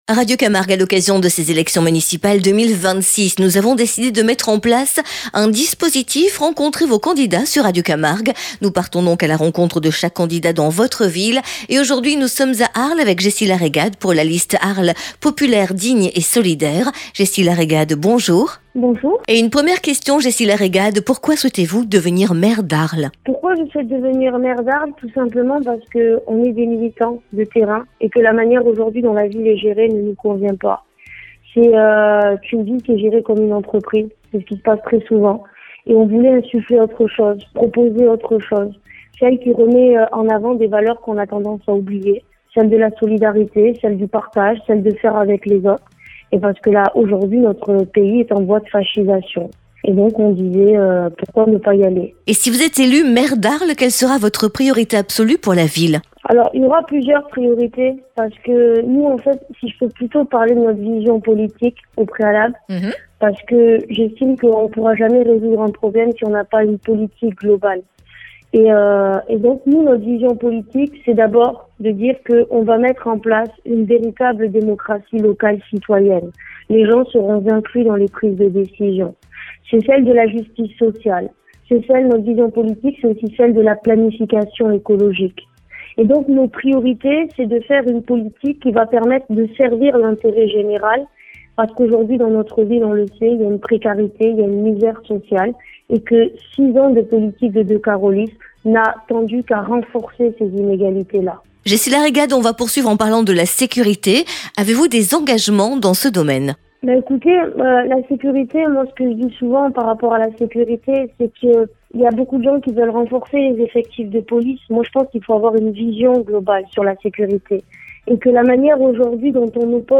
Municipales 2026 : entretien